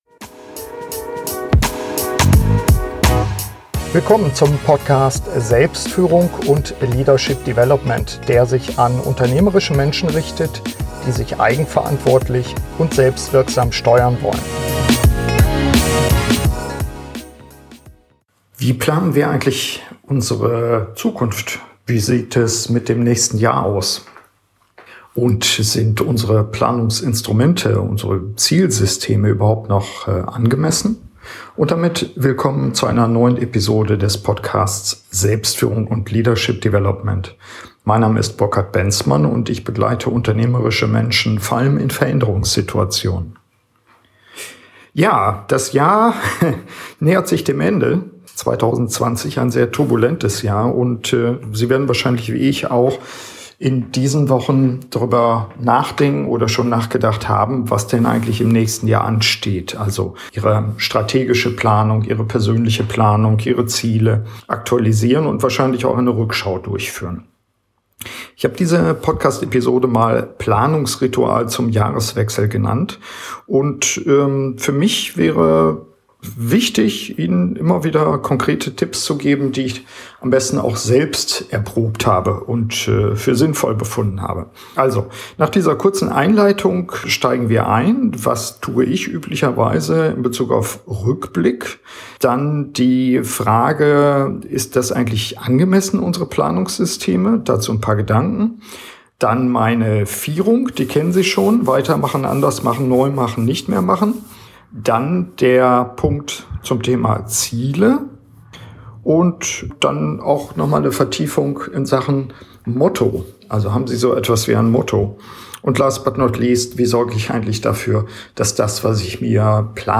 Die persönliche jährliche Planung muss nicht mühsam und kompliziert sein. In dieser Podcast-Soloepisode führe ich Sie durch mein Planungsritual.